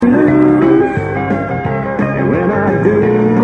E9th Tuning THIS IS ALL ONE CONTINUOUS PHRASE